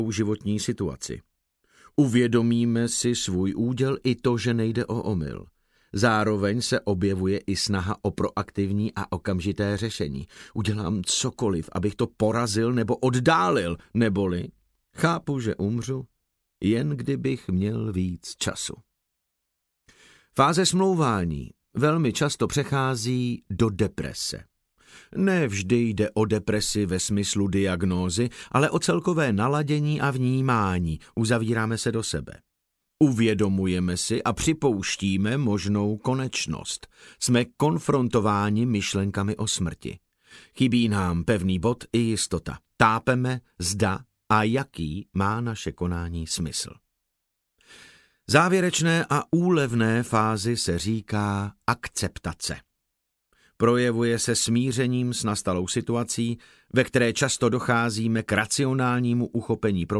Audiobook
Read: David Novotný